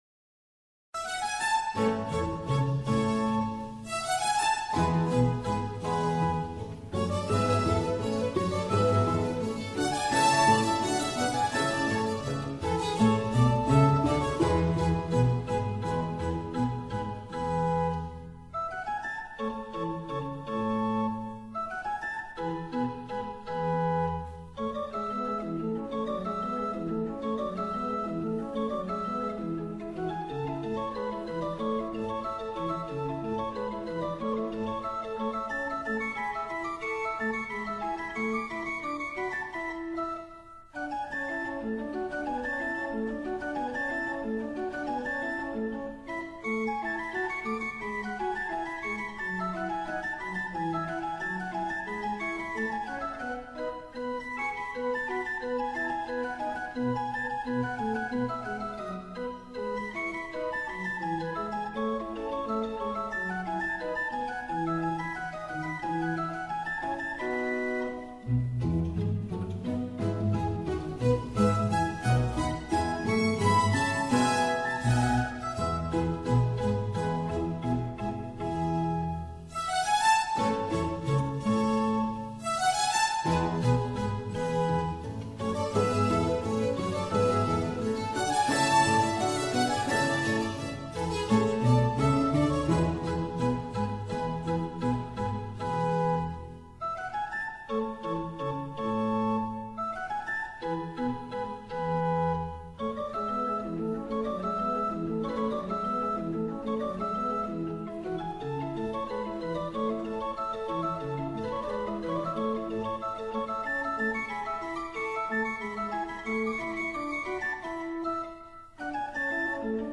전체적으로 유려함이 돋보이는 곡으로도 유명하다.
시냇물 졸졸 흐르는 계곡이나 나뭇잎이 바람에 산들거리는 산뜻한 풍경을 연상시키는 이 곡은 TV 방송 프로그램 안내를 내보낼 때, 아름다운 자연 풍경을 배경으로 이 음악이 곧잘 쓰여지곤 했기 때문에 우리들 귀에도 매우 익은 곡이다.
(Organ)
(Trumpet)
La Stravaganza Cologne Andrew Manze, Cond.